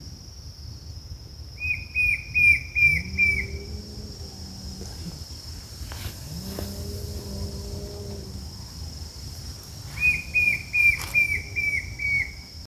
White-shouldered Fire-eye (Pyriglena leucoptera)
Detailed location: Reserva Natural Silvestre Parque Federal Campo San Juan
Condition: Wild
Certainty: Photographed, Recorded vocal